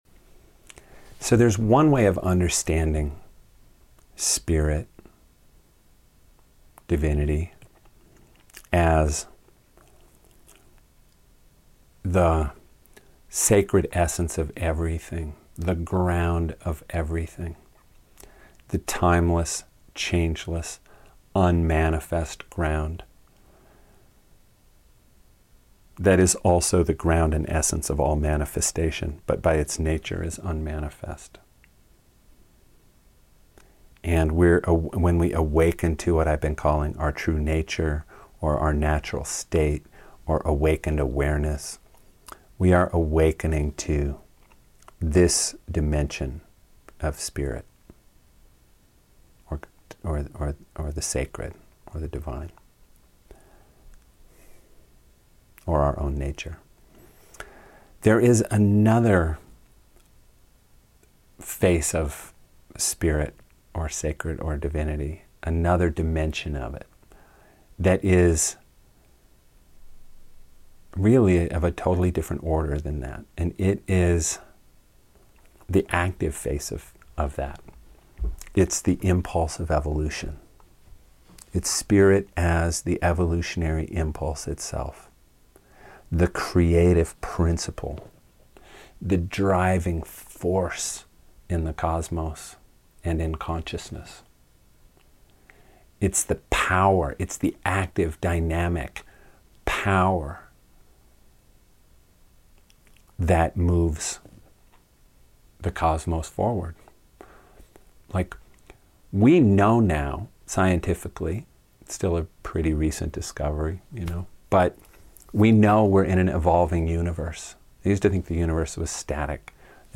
FREE AUDIO SEMINAR